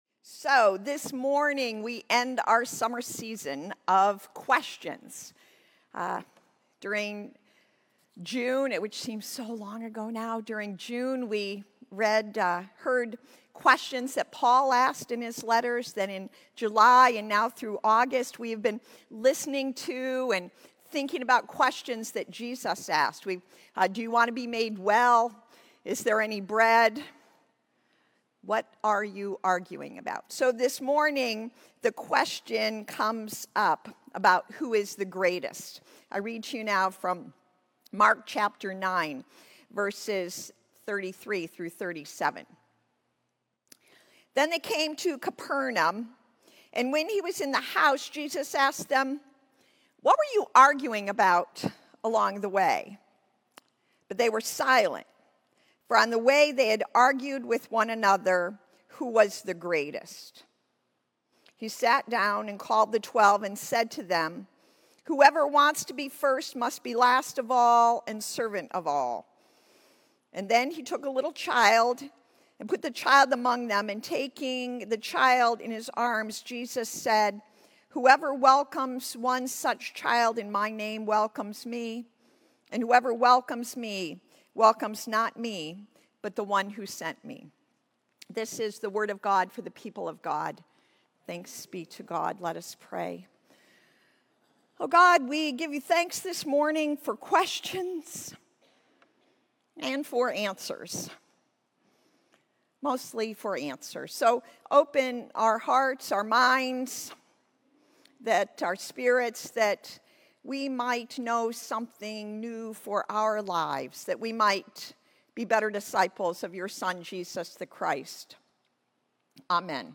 Sermons | Stone UMC